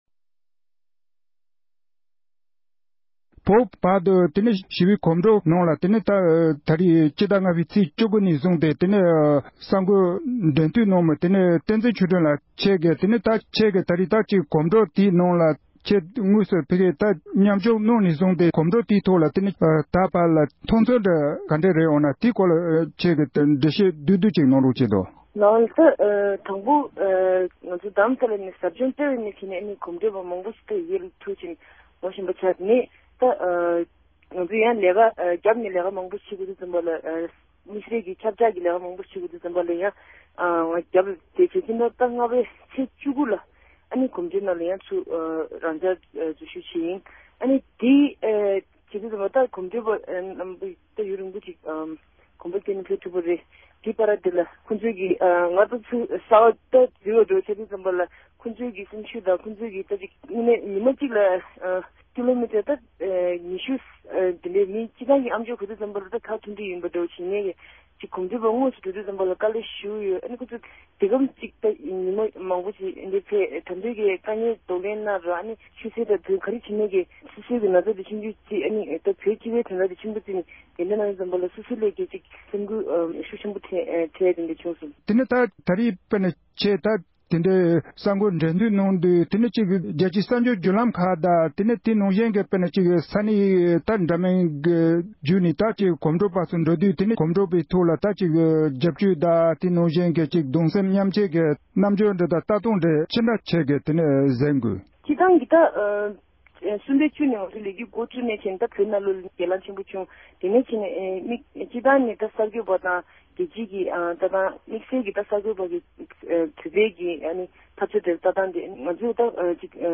ས་གནས་ནས་ཁ་པར་ཐོག